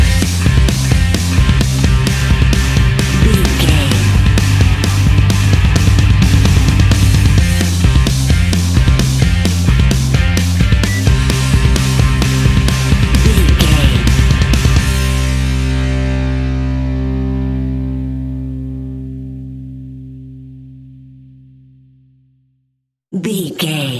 Ionian/Major
hard rock
punk metal
instrumentals
Rock Bass
heavy drums
distorted guitars
hammond organ